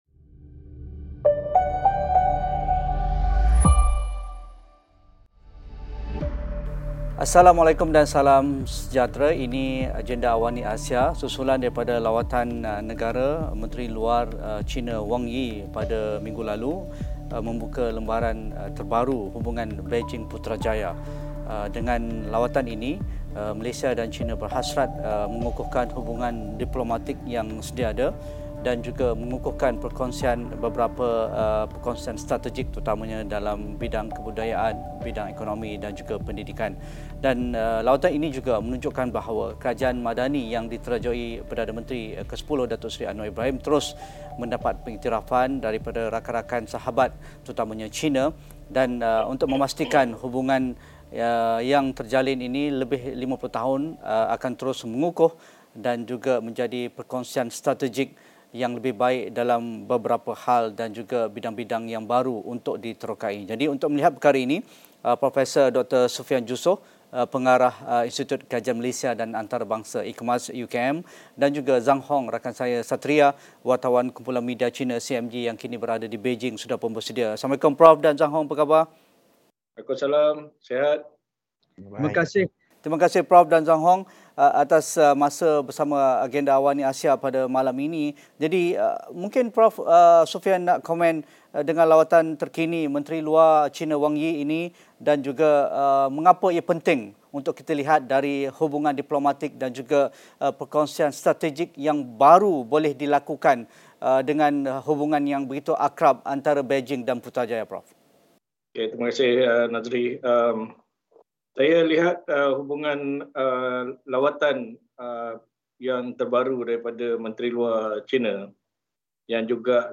Analisis pasca lawatan Menteri Luar China ke Malaysia. Apakah signifikan agenda mengukuhkan perkongsian strategik Malaysia – China demi kedamaian, kestabilan, pembangunan dan kemakmuran bersama? Diskusi Agenda Awani ASIA malam ini.